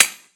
darkwatch/client/public/dice/sounds/surfaces/surface_metal9.mp3 at d543b173b41daf467b594069de77d073568c1e79
surface_metal9.mp3